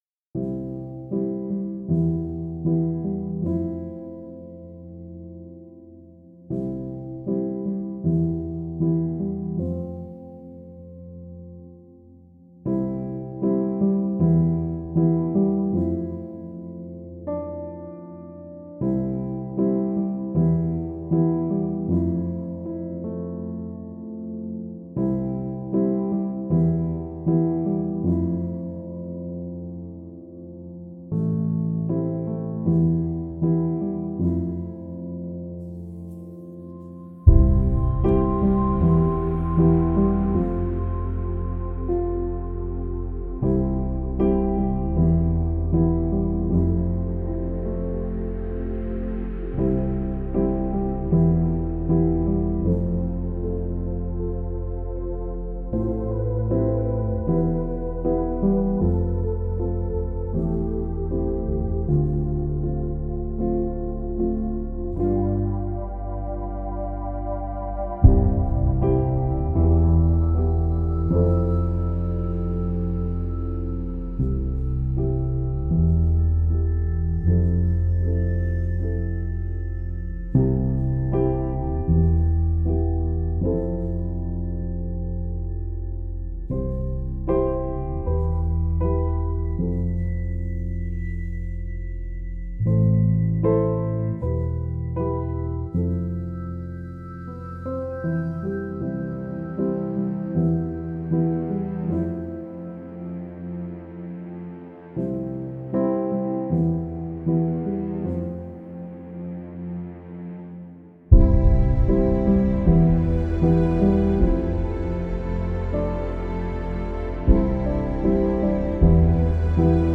Genre: TV & movie soundtrack.